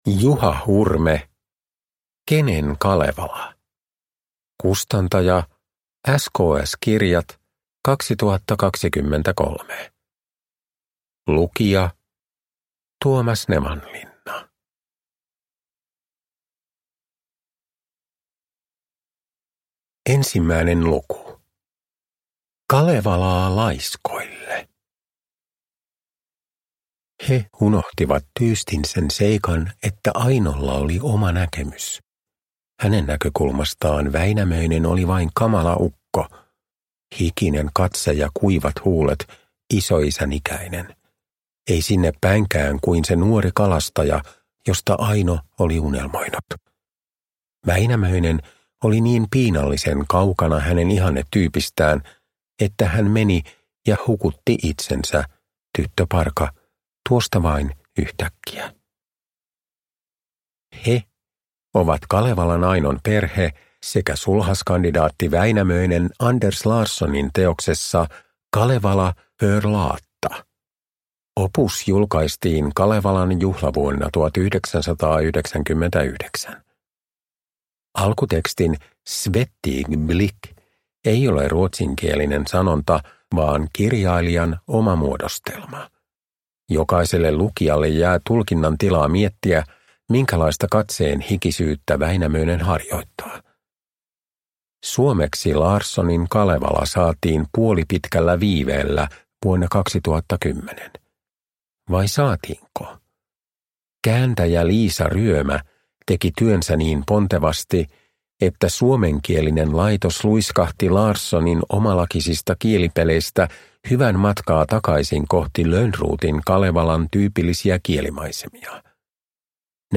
Produkttyp: Digitala böcker
Uppläsare: Tuomas Nevanlinna